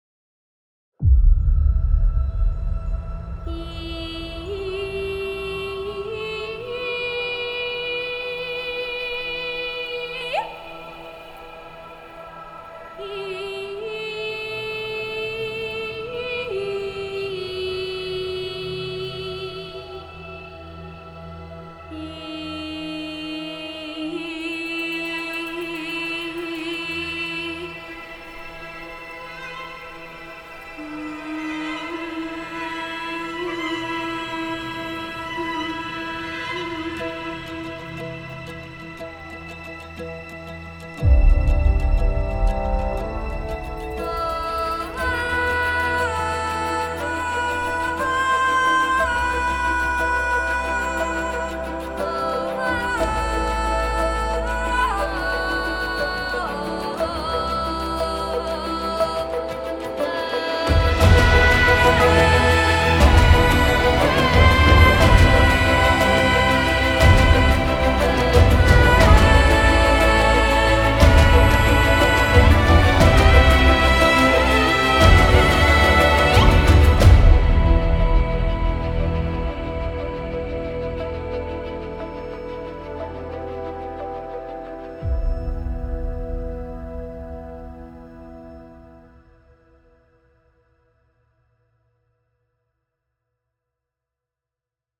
Traditional Bulgarian soloist